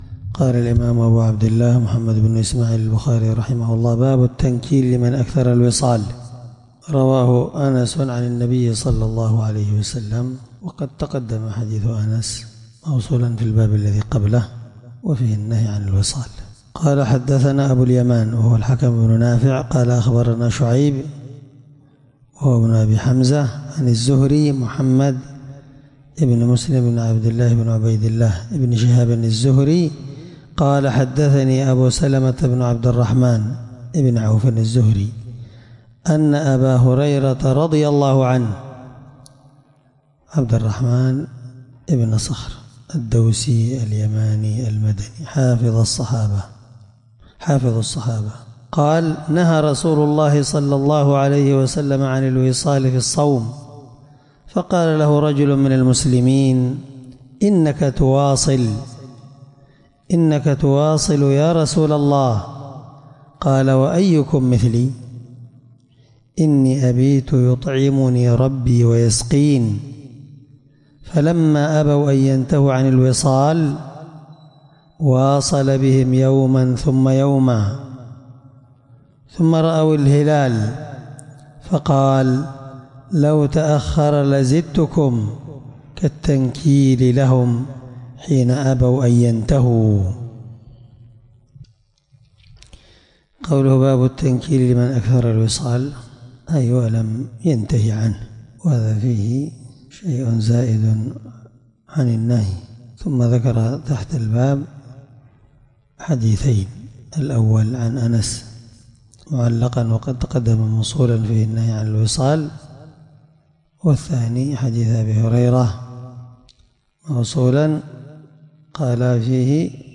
الدرس46 من شرح كتاب الصوم رقم(1965-1966)من صحيح البخاري